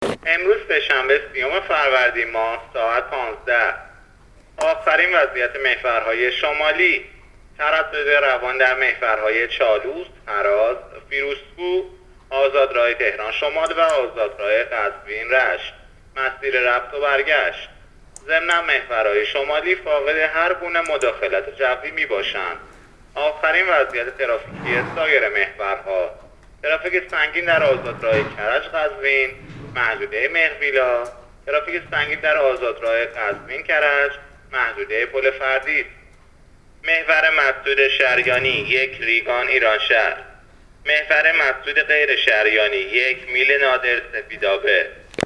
گزارش رادیو اینترنتی از آخرین وضعیت ترافیکی جاده‌ها تا ساعت ۱۵ سی‌ام فروردین؛